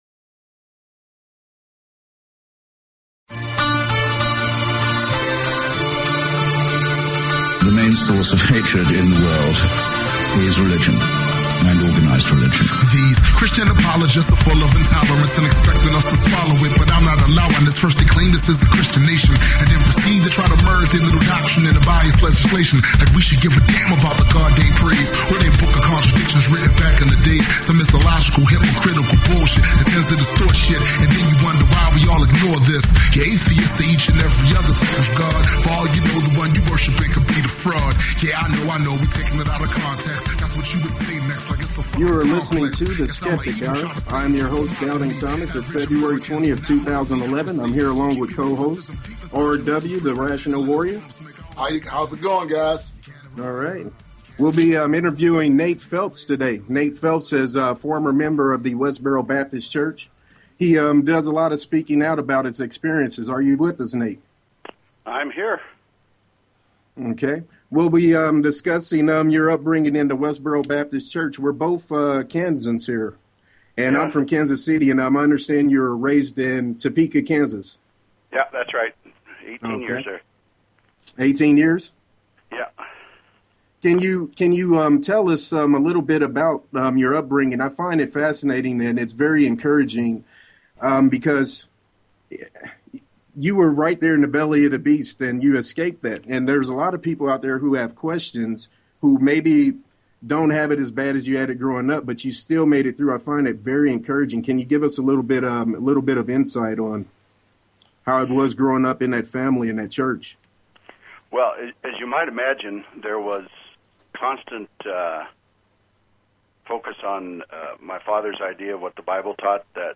The Skeptic Hour is a radio show dedicated to helping people begin to think critically about everything.